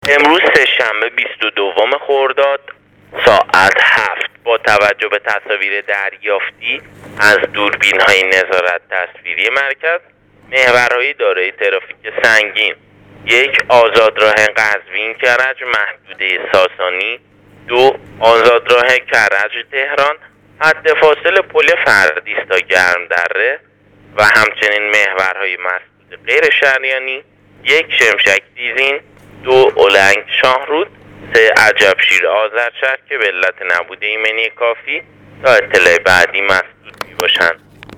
آخرین وضعیت محورهای مواصلاتی کشور را از طریق رادیو اینترنتی پایگاه خبری وزارت راه و شهرسازی بشنوید.
رادیو اینترنتی پایگاه خبری وزارت راه و شهرسازی: